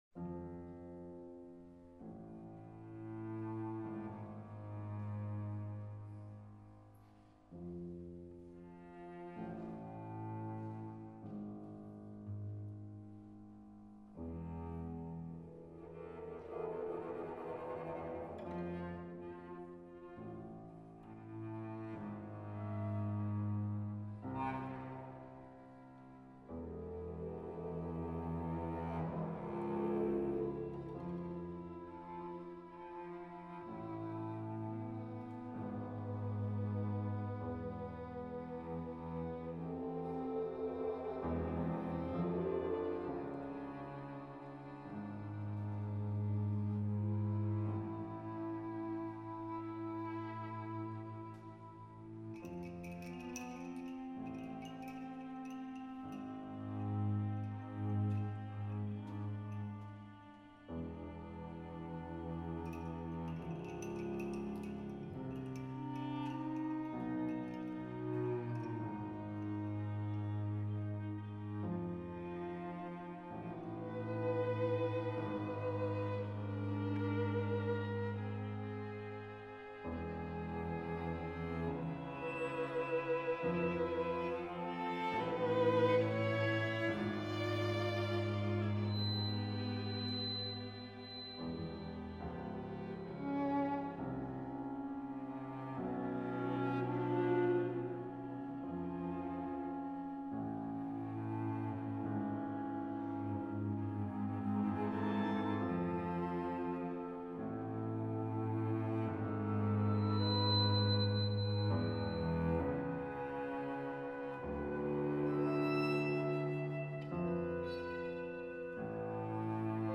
for flute, clarinet, percussion, piano, violin, viola, and cello
The quasi-passacaglia motion in this movement allows the continuation of the melodic outpouring of the third movement.
Performance by the USC Contemporary Music Ensemble